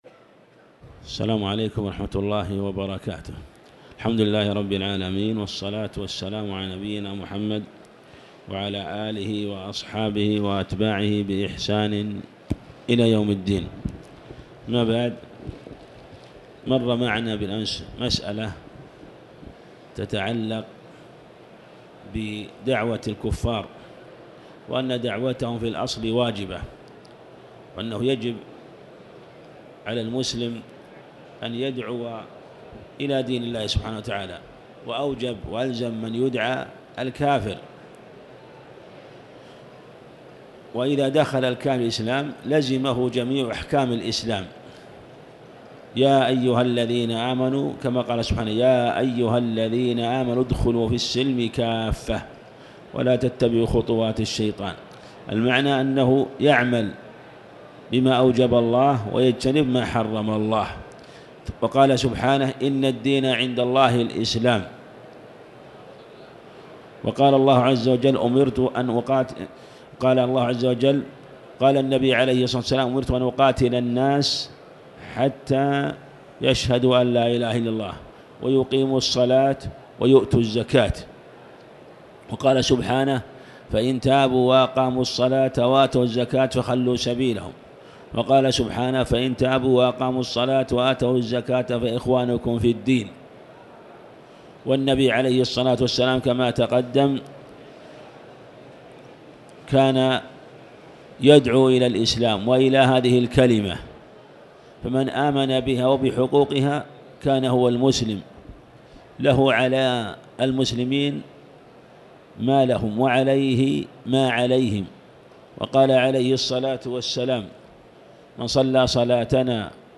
تاريخ النشر ٧ رمضان ١٤٤٠ هـ المكان: المسجد الحرام الشيخ